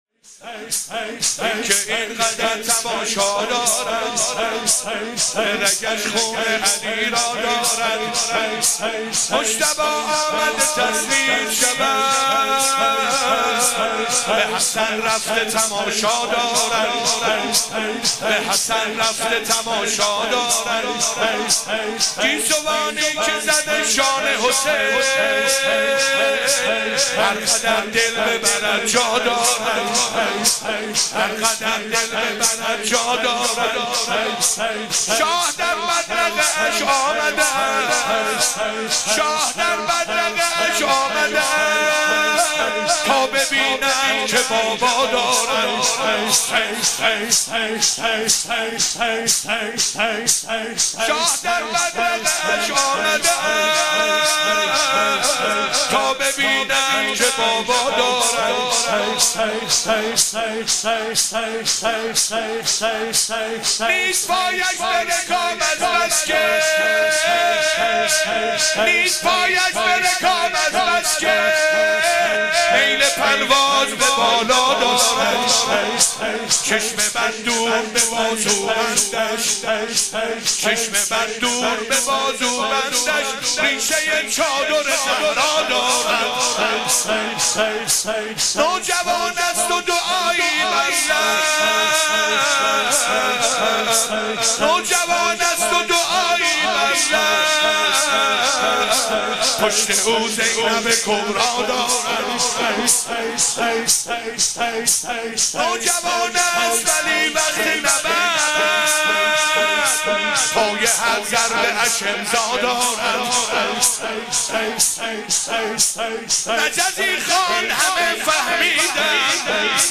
عقیق:حسینیه موج الحسین(ع)شب ششم محرم95
شور شب ششم